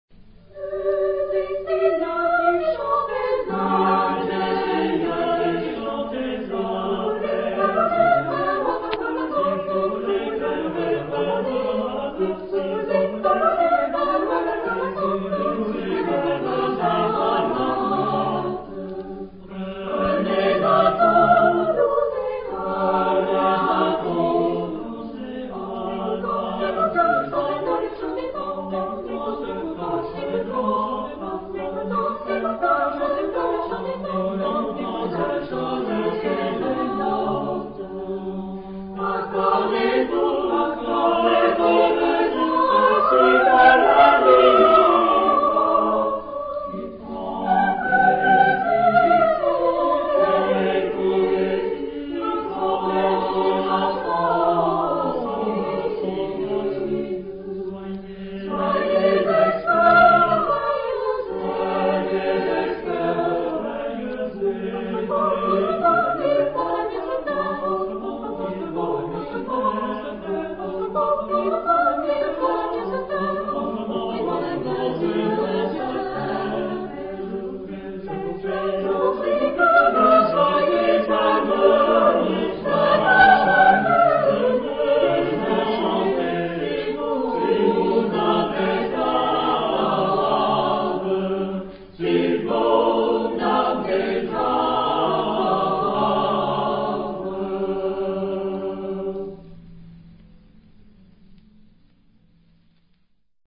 Genre-Stil-Form: Renaissance ; weltlich
Chorgattung: SATB  (4 gemischter Chor Stimmen )